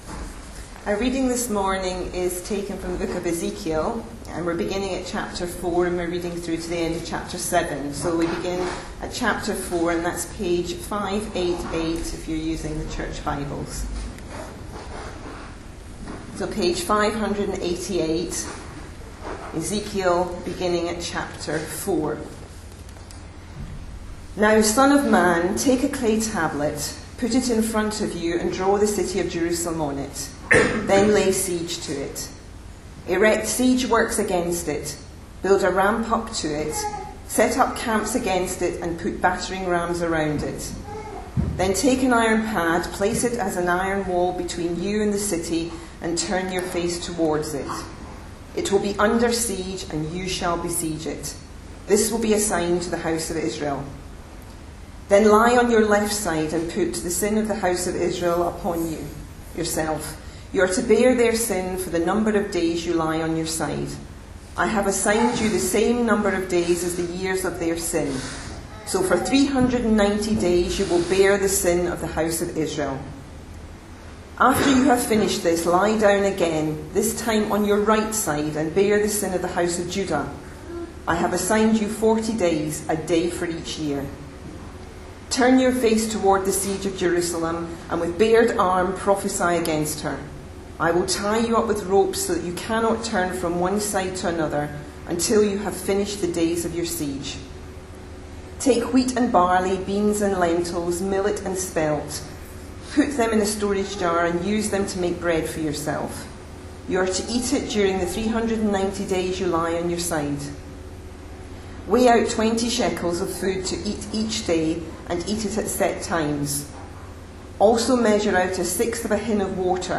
A sermon preached on 8th June, 2014, as part of our Ezekiel series.